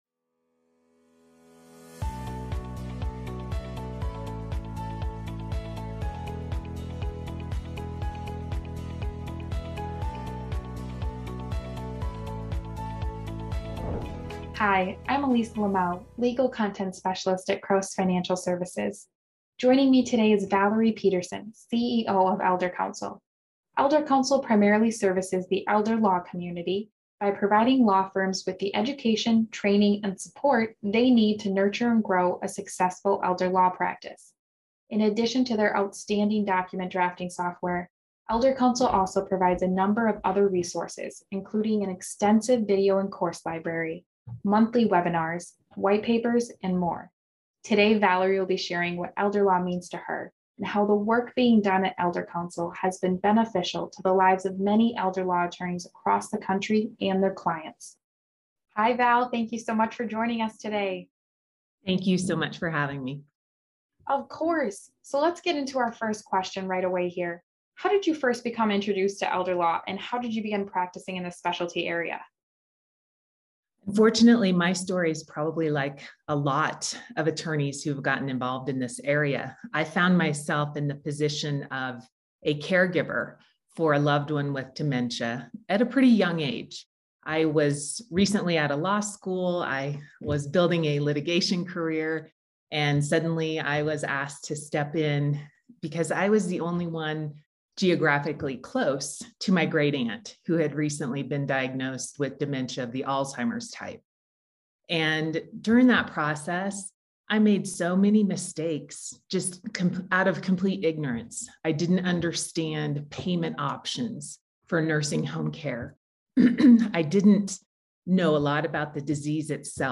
If you are thinking about adding elder law to your practice, this interview is for you! Watch the full session to learn more.